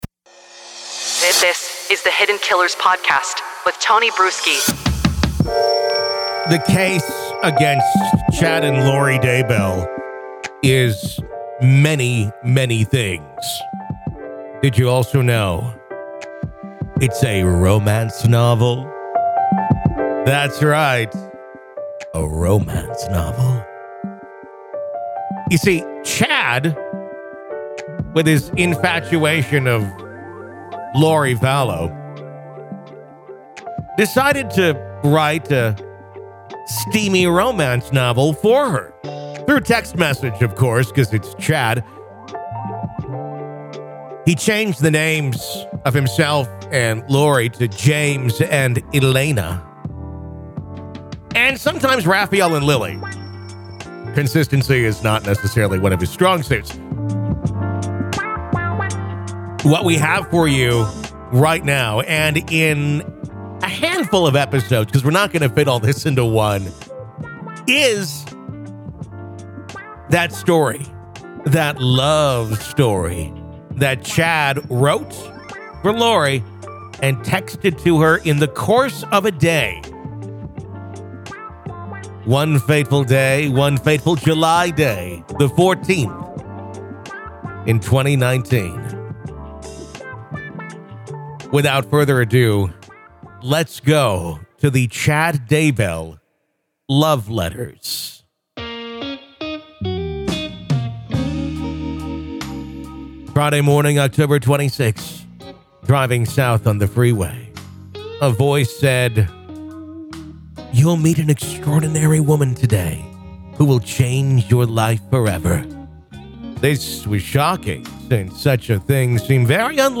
In a captivating audio performance, the intimate love letters penned by Chad Daybell to Lori Vallow are dramatically enacted for listeners. This unique experience provides an unprecedented insight into the deep and complex relationship between the two.